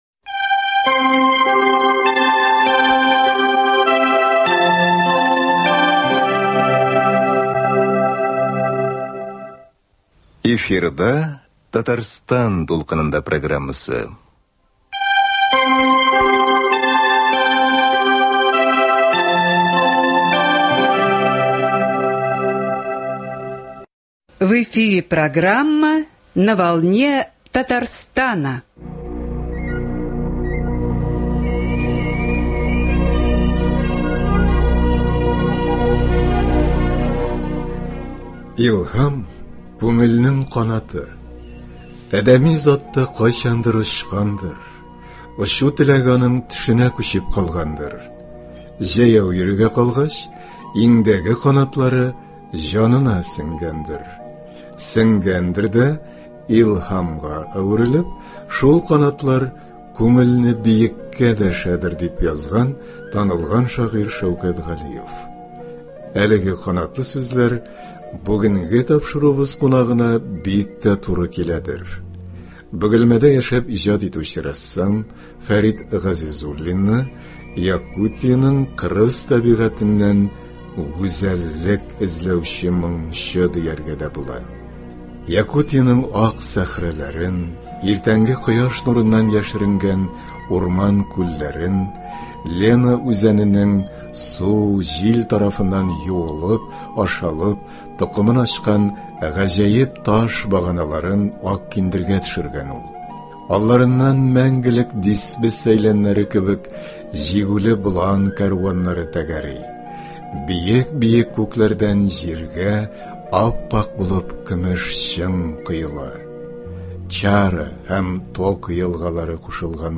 «Матурлык турында сөйләшү». Студиябез кунагы